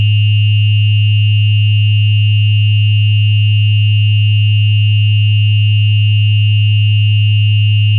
Результатом є 8-секундний стереозапис синтетичної події етичного вагання при γ ≈ 0,724.
Лівий канал несе стрес — етичне навантаження, що надходить. Правий канал несе напругу — реакцію системи, затриману на γ радіан.
Високочастотний артефакт на 2800 Гц — це колапс смуги пропускання — момент, коли система більше не може встигати.
Базова частота: 105 Гц.
Фазове відставання: 2,27 радіана (130,32°).
Ліве вухо сприймає запитання. Праве вухо сприймає відповідь із затримкою.